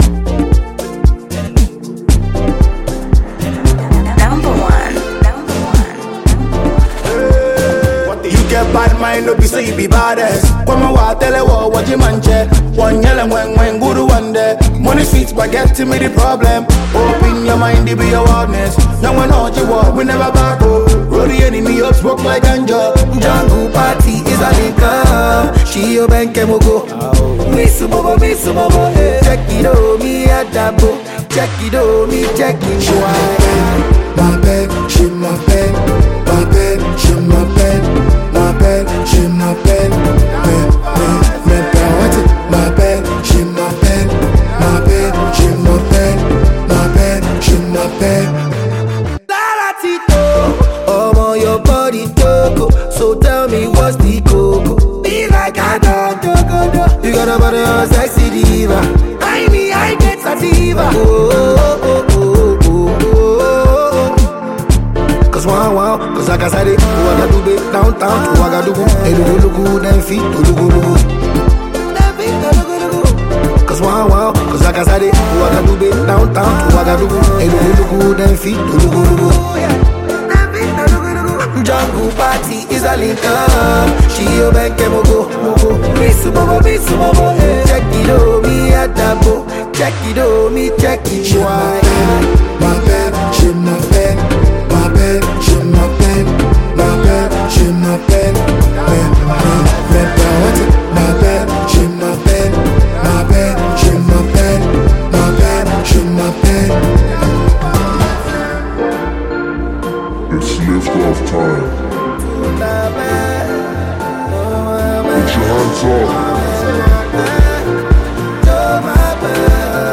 Ghanaian afrobeat act